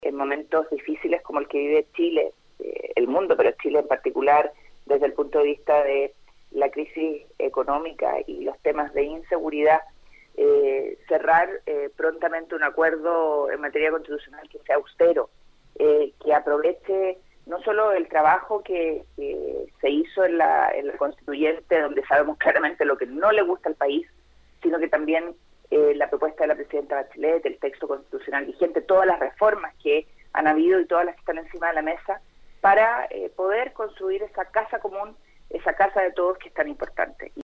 En entrevista con Nuestra Pauta, la parlamentaria indicó que están a la espera de la resolución del Servel para comenzar a «inscribir y sumar voluntades a lo largo del país» y destacó «la recepción, el cariño y la cantidad de gente que nos ha escrito y llamado, que nos para en la calle y nos pide que les avisemos cómo ser parte de este nuevo partido».